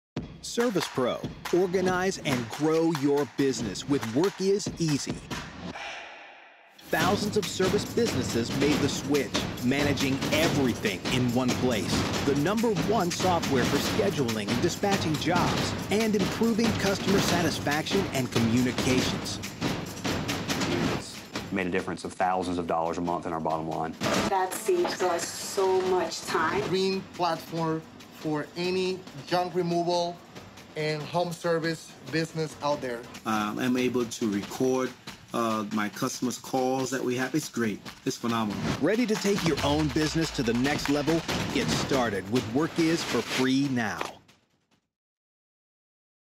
Voice over and Dubbing Artist
American voice | north American voice sample | Usa accent
american accent male voice over.mp3